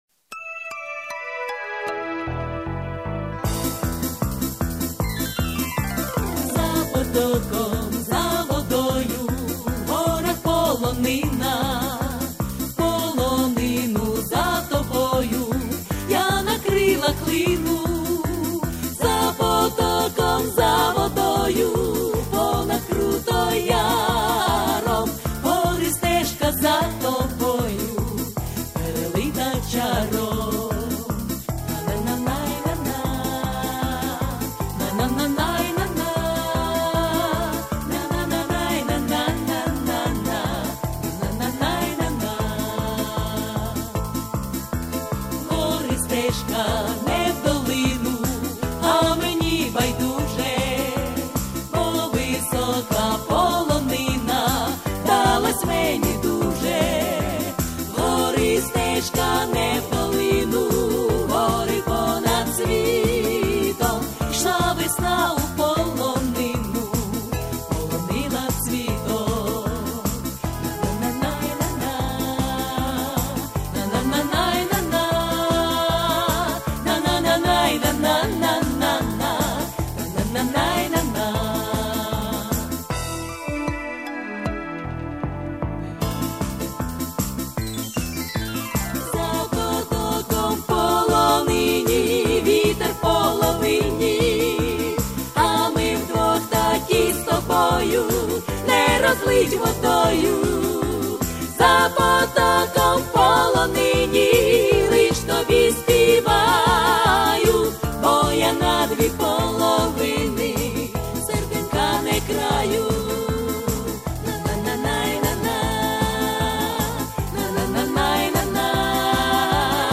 Всі мінусовки жанру Традиційні UA
Плюсовий запис